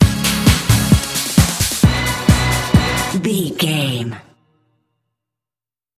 Epic / Action
Fast paced
Ionian/Major
Fast
synthesiser
drum machine
80s